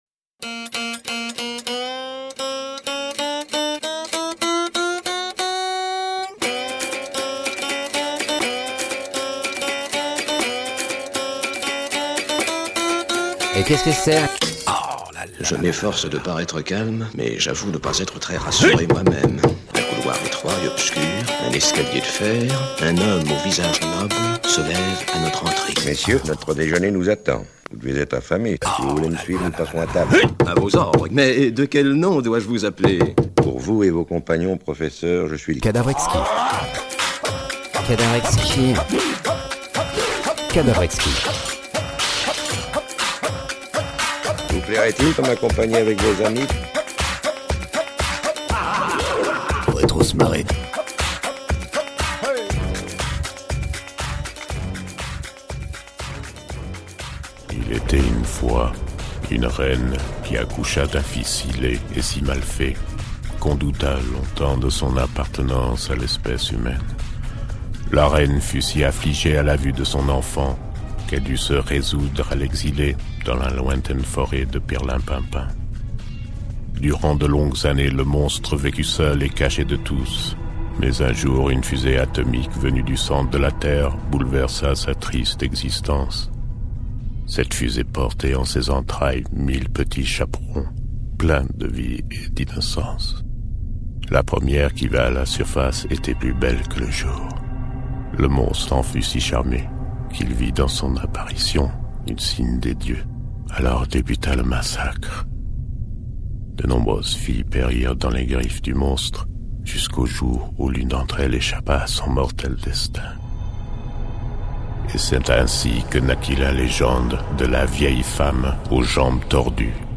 Un Grand Cerf Brame En Marchant
Nature, forest, birds, wind